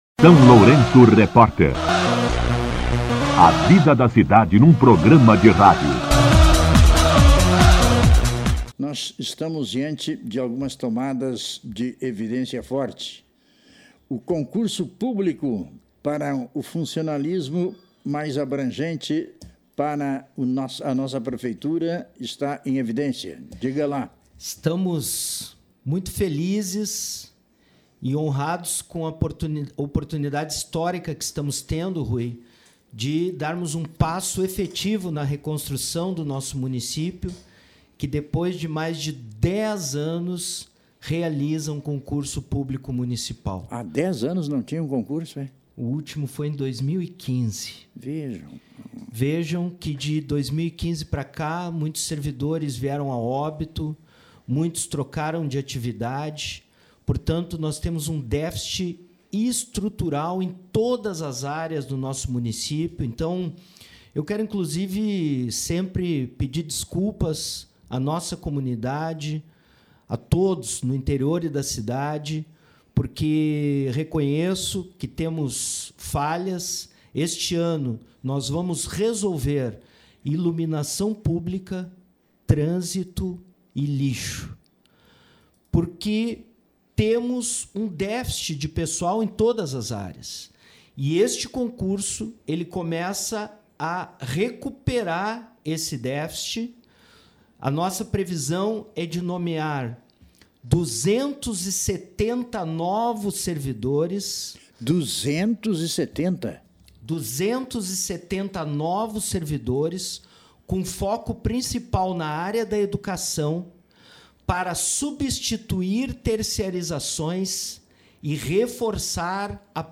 Entrevista com o prefeito municipal Zelmute Marten
O prefeito municipal Zelmute Marten participou nesta terça-feira (03) da programação do SLR RÁDIO para falar sobre o Concurso Público Municipal 2026.
entrevista-03.03-prefeito-zelmute.mp3.mp3